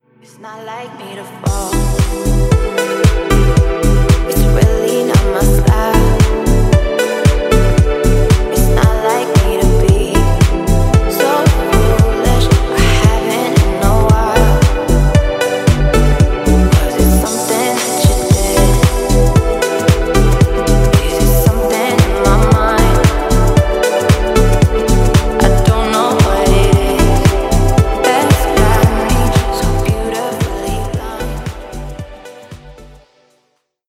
• Качество: 320, Stereo
красивые
deep house
dance
спокойные
club
приятный женский голос